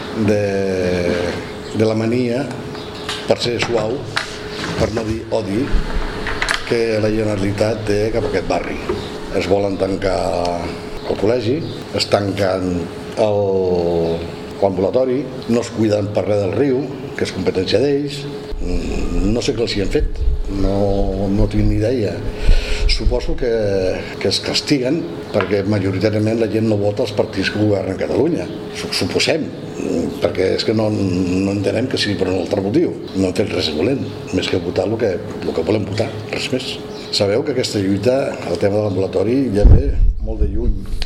Declaracions: